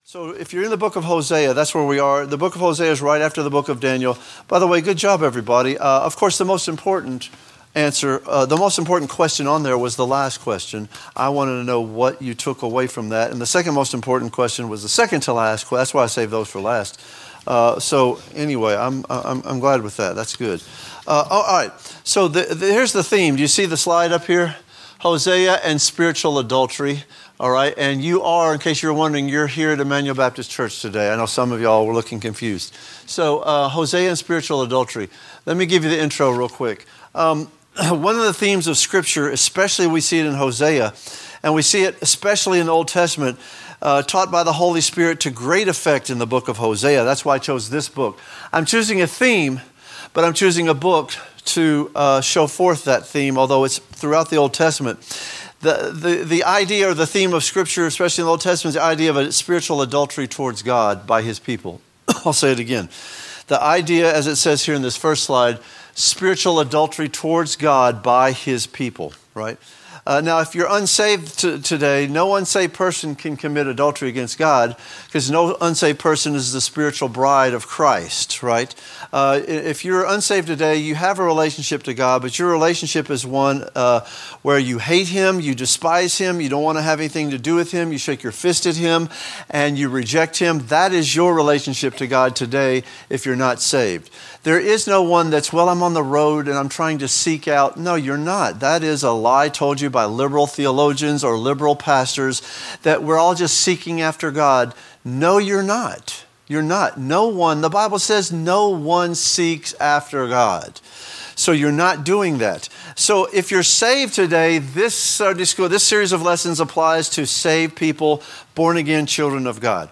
A message from the series "Spiritual Adultry." A study on spiritual adultry